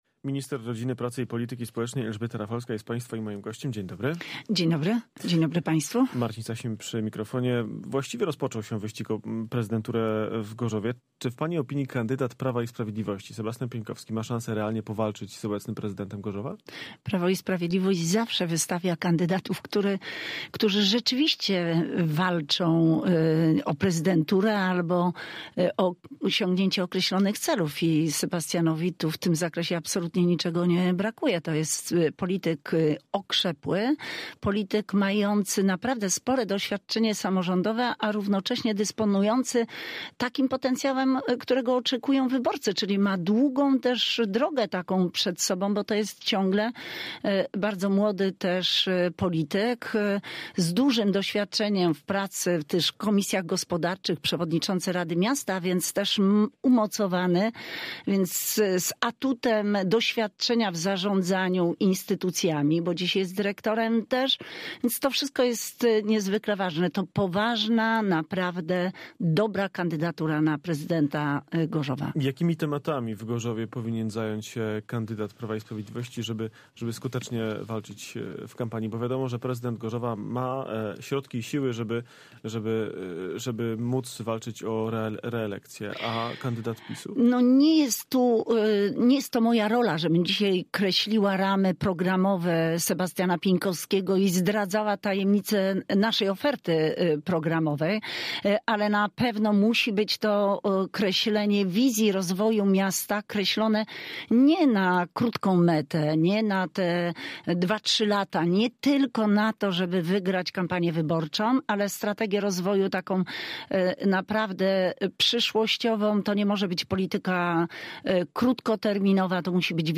Z minister rodziny, pracy i polityki społecznej rozmawia